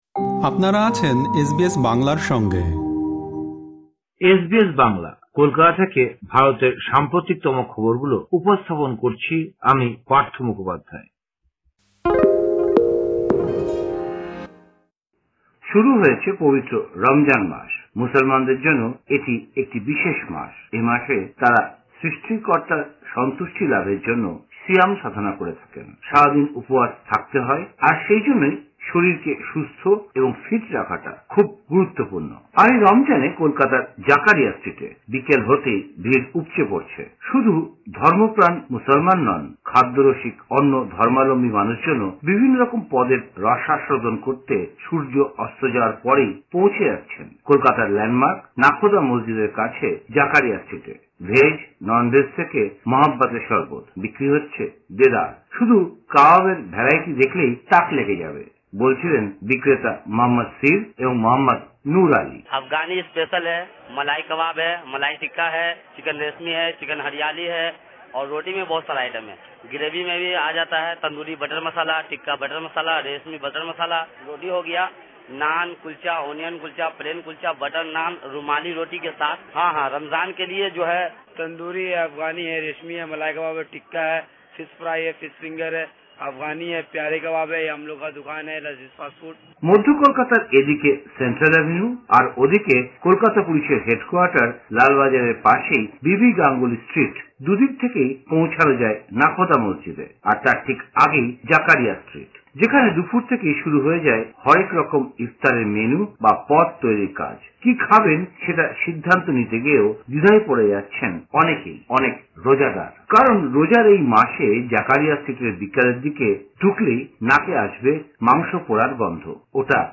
সেই জাকারিয়া স্ট্রিটের ওপর একটি প্রতিবেদন শুনতে ক্লিক করুন উপরের অডিও-প্লেয়ারটিতে।